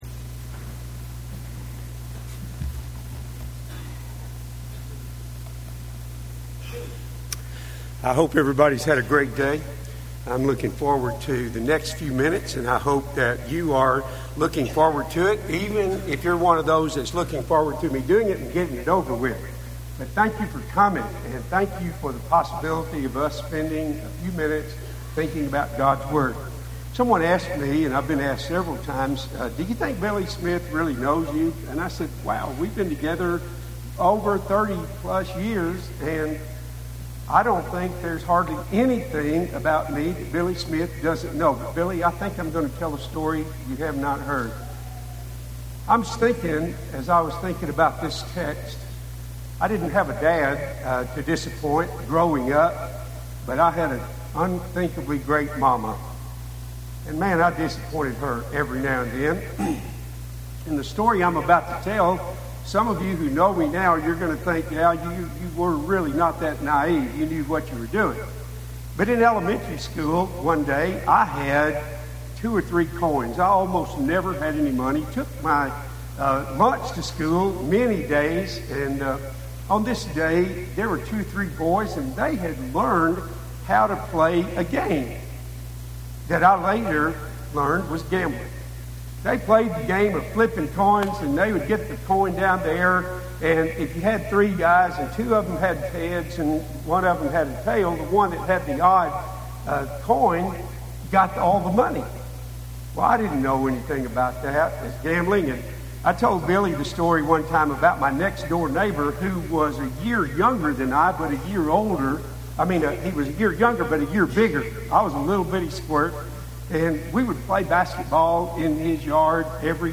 Moses – The Beggar – Henderson, TN Church of Christ